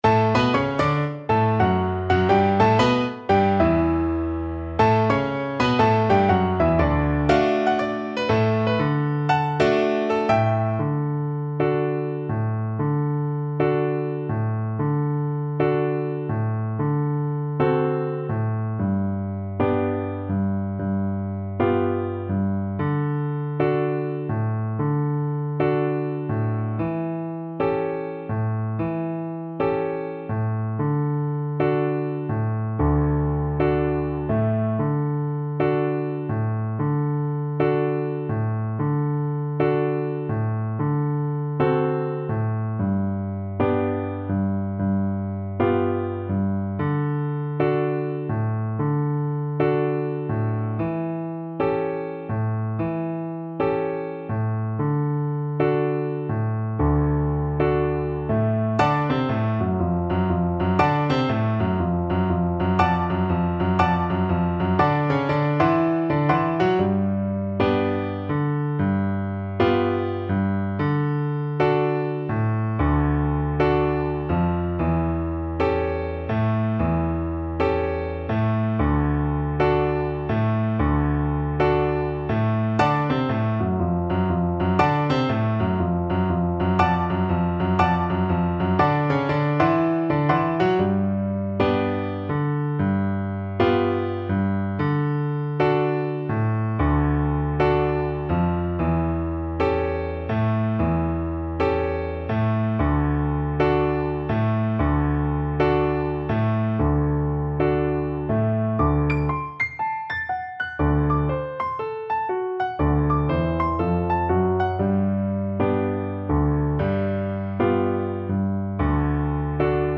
Moderato = 120
Jazz (View more Jazz Violin Music)